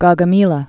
(gôgə-mēlə)